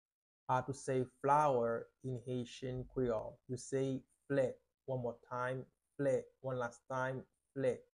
How to say “Flower” in Haitian Creole – “Flè” pronunciation by a native Haitian Teacher
“Flè” Pronunciation in Haitian Creole by a native Haitian can be heard in the audio here or in the video below: